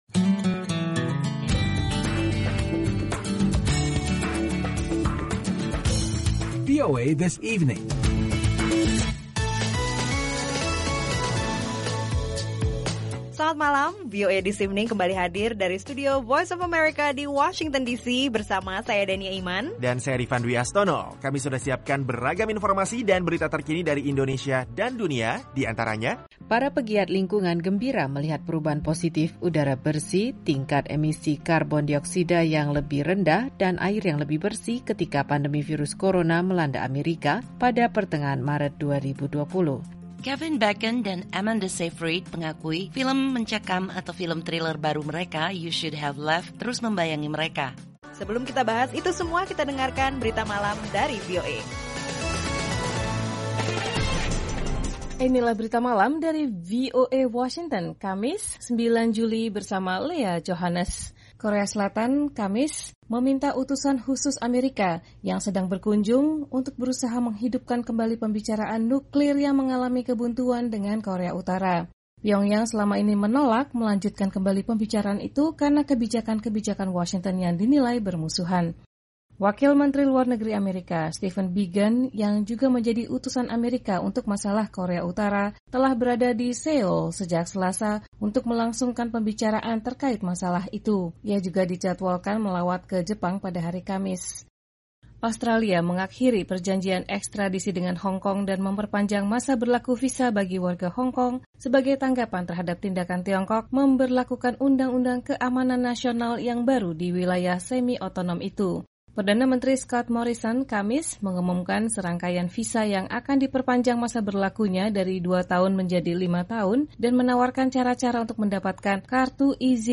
Akhiri kesibukan hari kerja Anda dengan rangkuman berita terpenting dan informasi menarik yang memperkaya wawasan Anda dalam VOA This Evening.